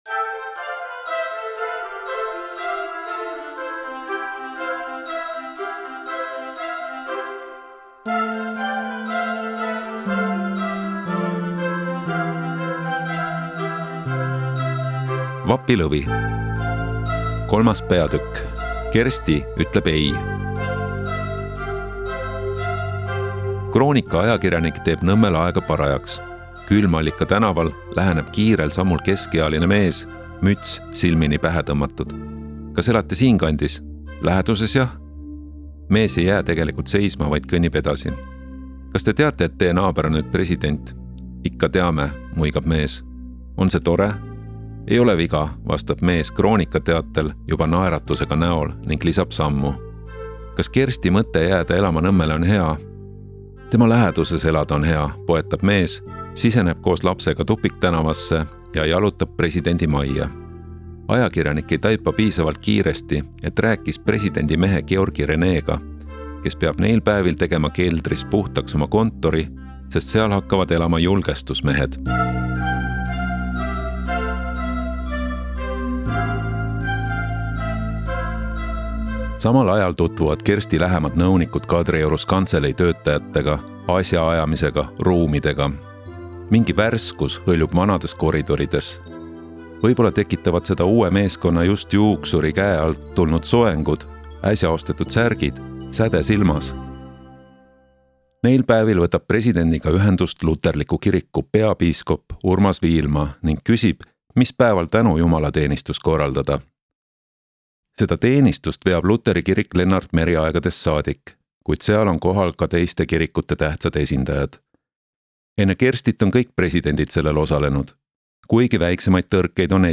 Vapilõvi-III-osa-Kersti-Ei-audiolugu.mp3